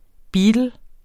Udtale [ ˈbiːdəl ]